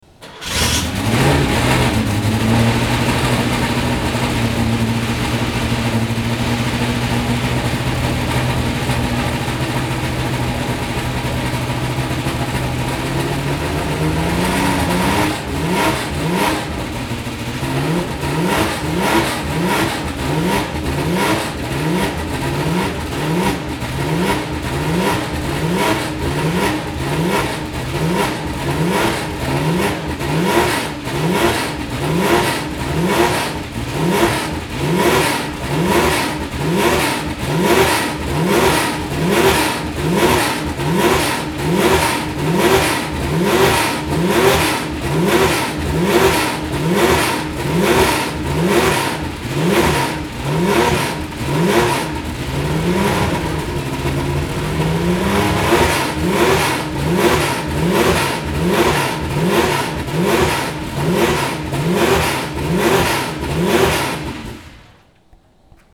Porsche Sound Night 2017 - a loud night at the museum (Event Articles)